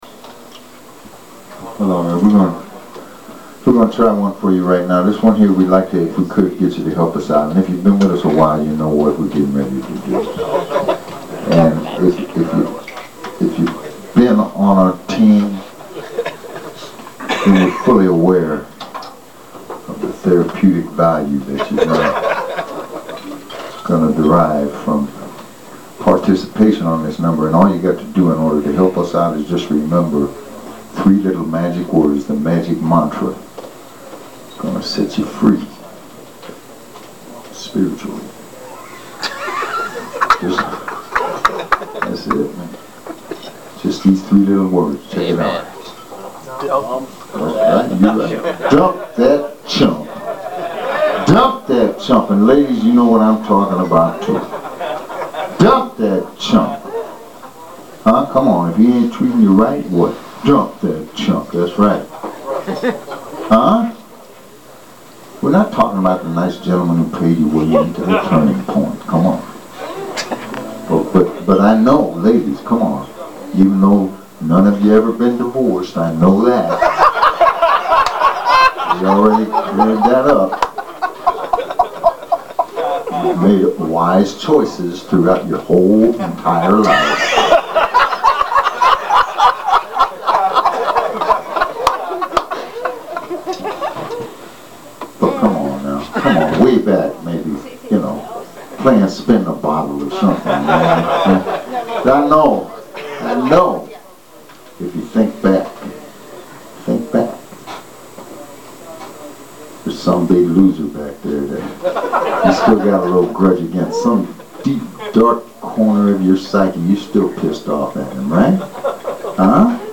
Electric Blues Harp
At the Turning Point Cafe, Piermont, NY